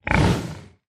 Minecraft Version Minecraft Version snapshot Latest Release | Latest Snapshot snapshot / assets / minecraft / sounds / mob / hoglin / idle8.ogg Compare With Compare With Latest Release | Latest Snapshot